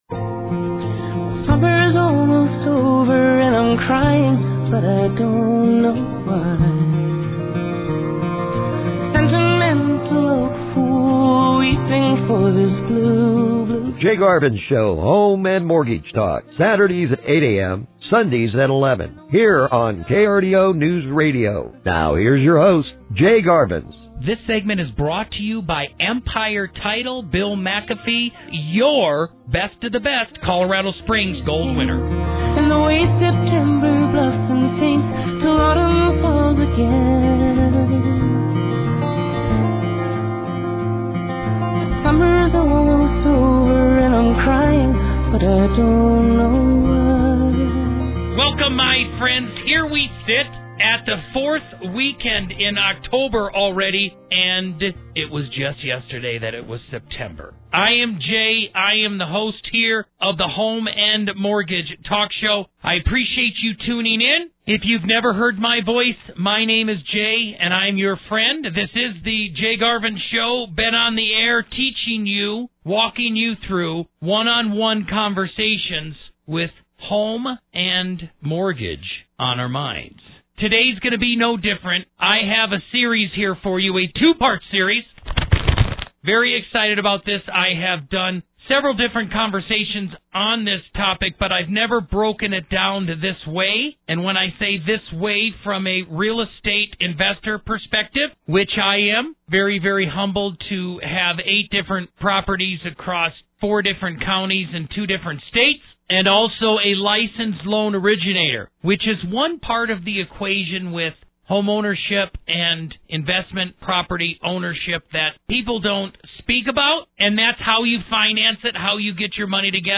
Here on KRDO News Radio 105.5FM and 1240AM.